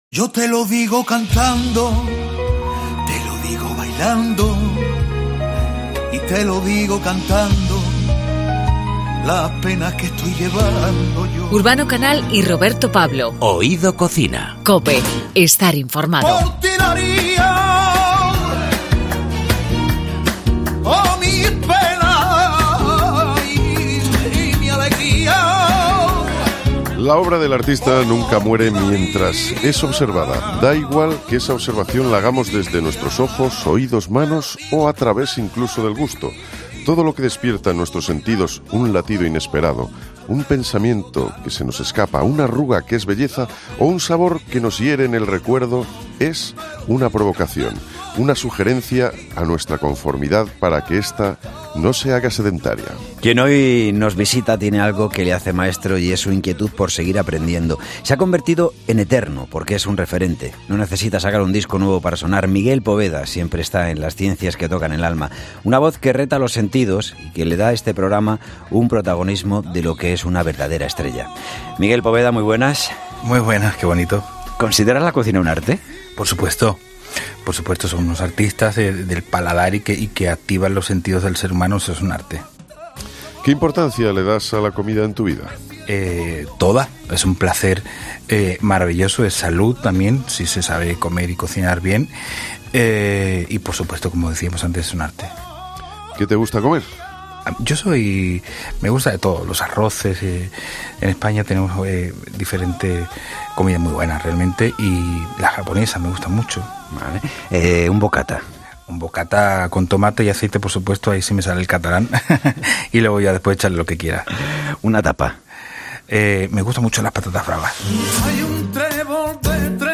El cantaor Miguel Poveda nos lo dice cantando, le gusta la cocina. Para él quien tiene la capacidad de activar los sentidos a través del paladar merece la denominación de artista.